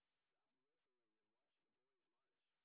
sp23_white_snr20.wav